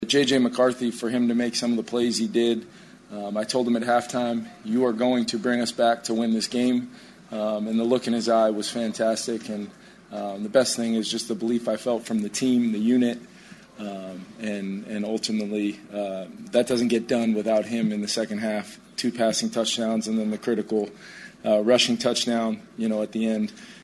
Vikings head coach Kevin O’Connell on the play of QB J.J. McCarthy in his first NFL start.